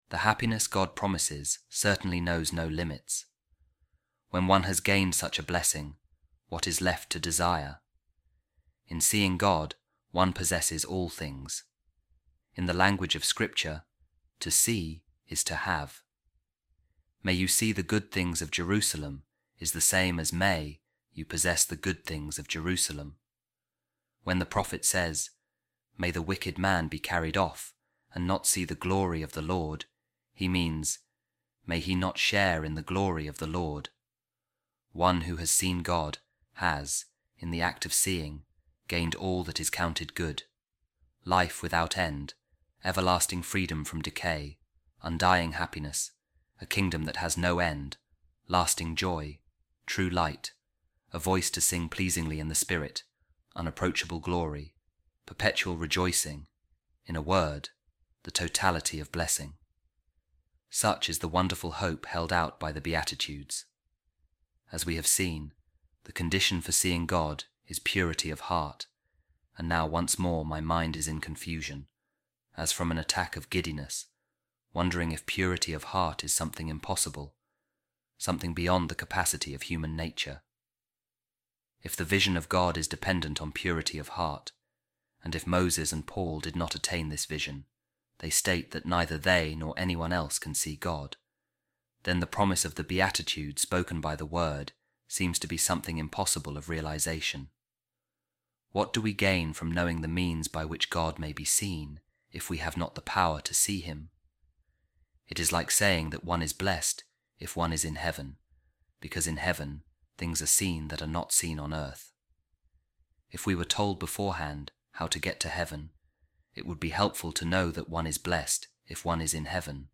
A Reading From The Homilies Of Saint Gregory Of Nyssa On The Beatitudes | The Hope Of Seeing God